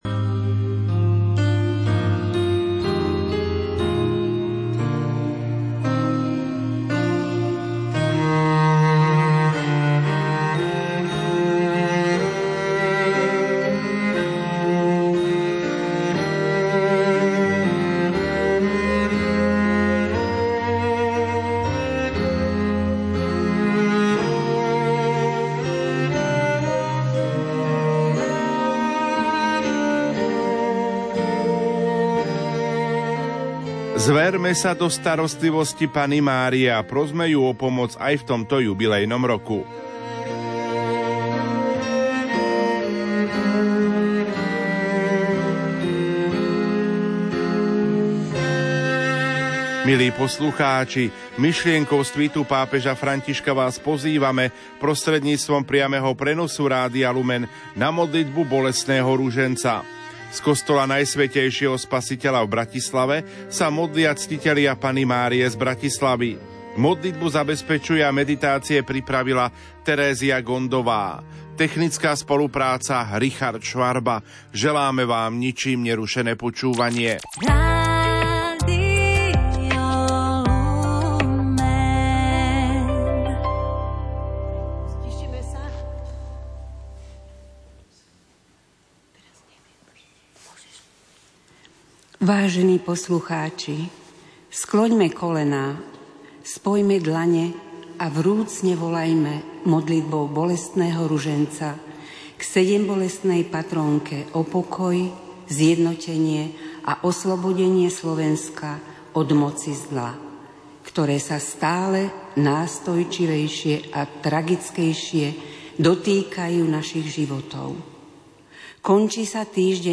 Bolestný ruženec sa modlia Ctitelia Panny Márie z kostola Najsvätejšieho Spasiteľa v Bratislave.